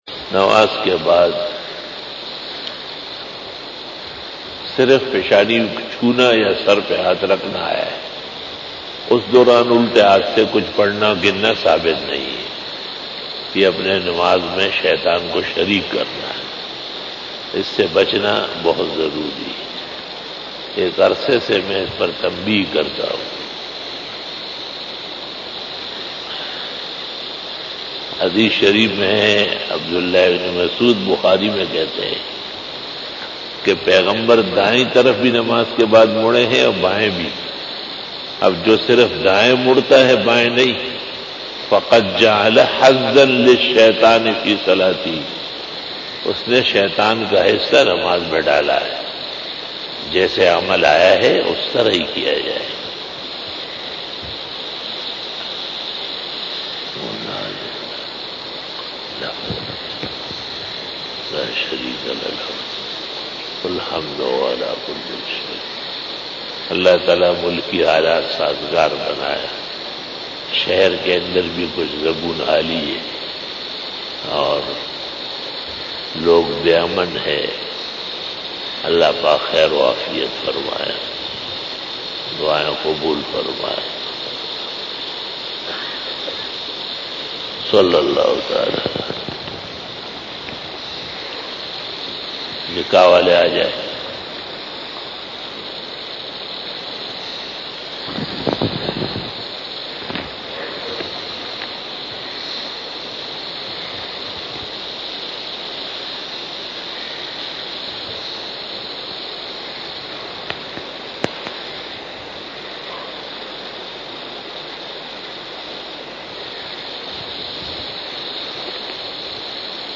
After Namaz Bayan
بیان بعد نماز عصر بروز جمعہ